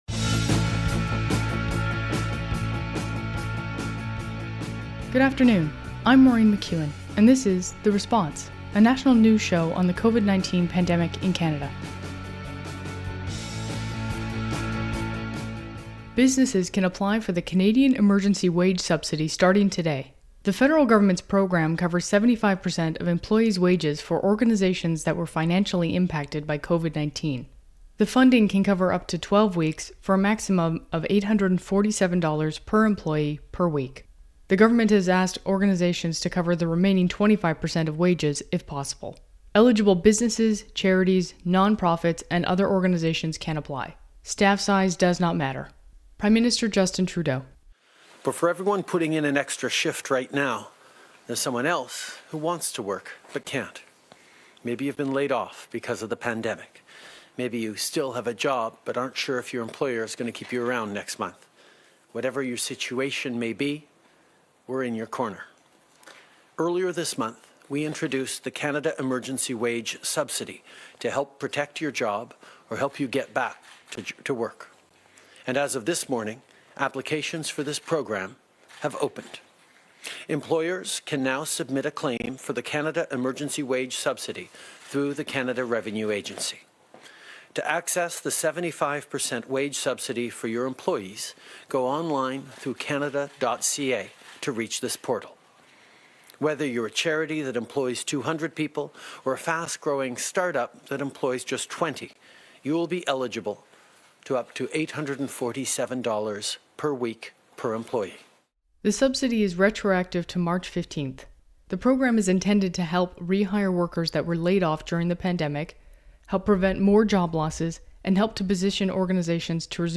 National News Show on COVID-19
Recording Location: Ottawa
Credits: Audio clips: Canadian Public Affairs Channel.
Theme: "Headed South" by The Hours.
Type: News Reports